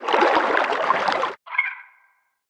Sfx_creature_penguin_idlesea_B_01.ogg